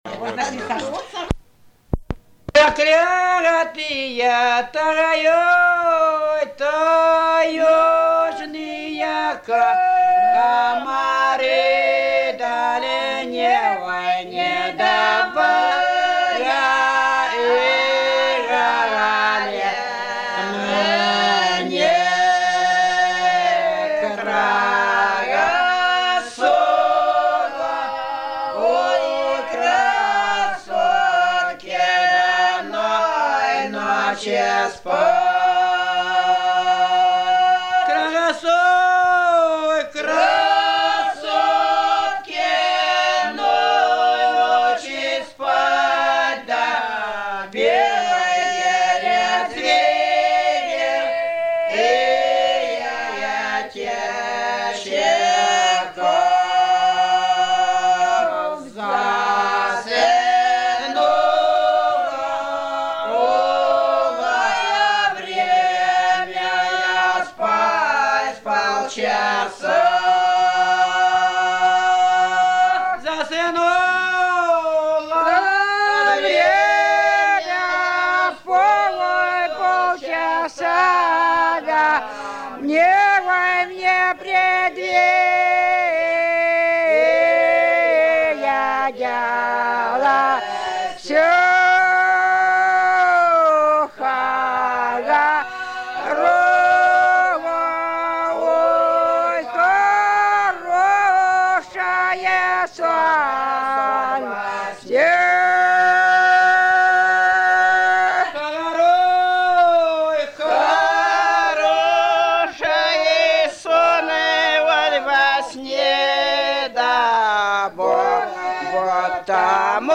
протяжная